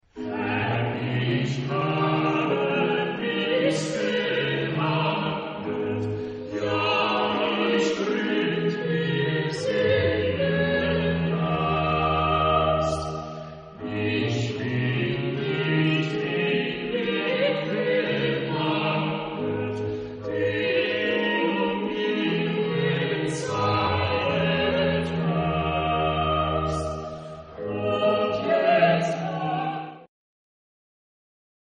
Genre-Style-Form: Baroque ; Sacred ; Chorale
Type of Choir: SATB  (4 mixed voices )
Tonality: A minor